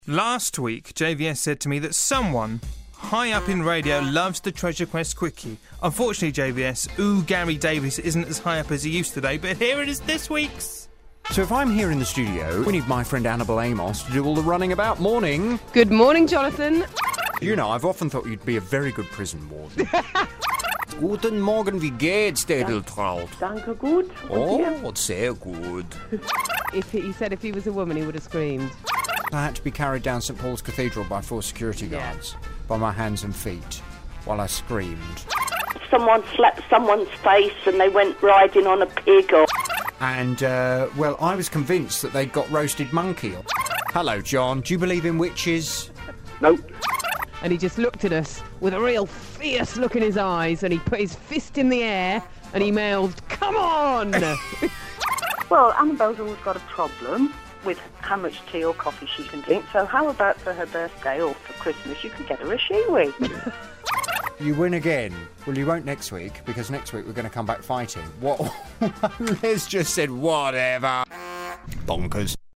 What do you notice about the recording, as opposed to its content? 3 hours of BBC Radio Northampton's Treasure Quest in around a minute.